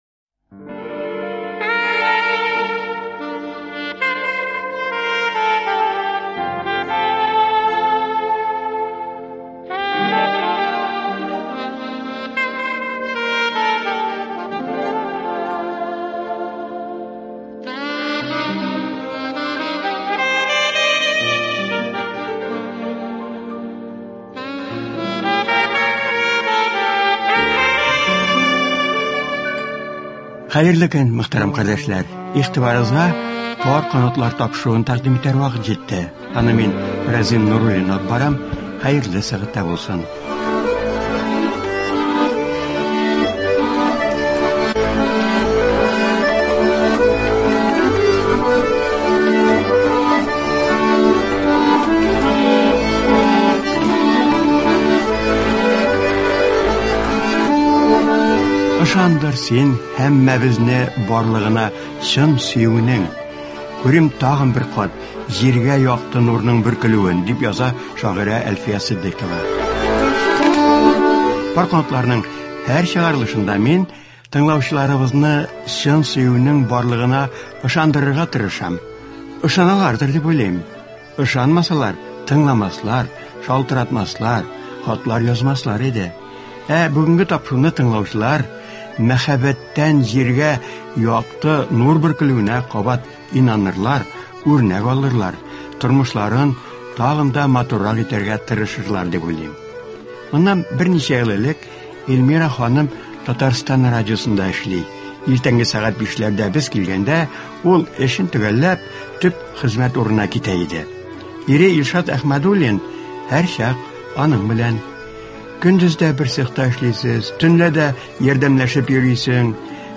Тыңлаучыларга алар башкаруында җырлар тәкъдим ителә.